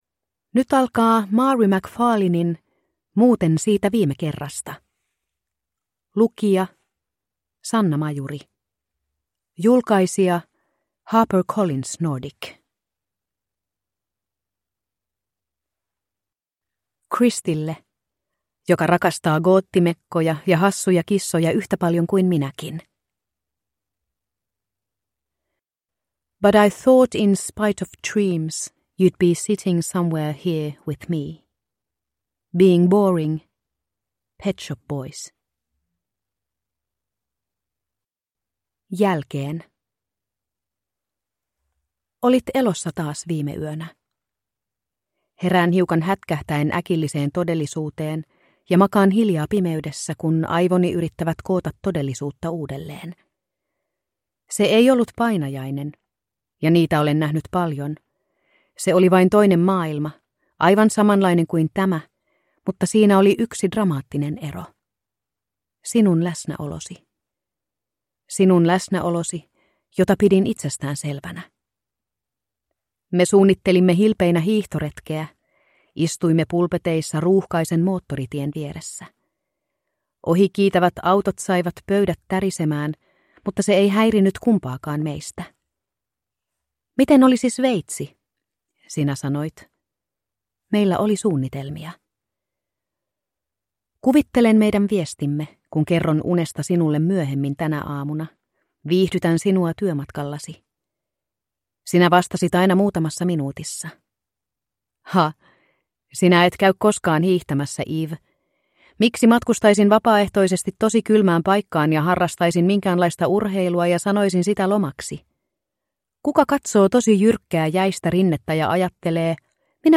Muuten siitä viime kerrasta – Ljudbok – Laddas ner